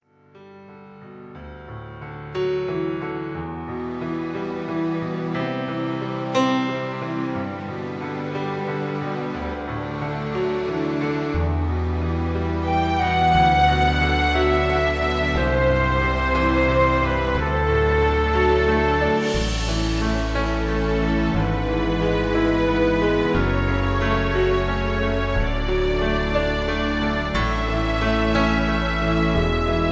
Key-Em-A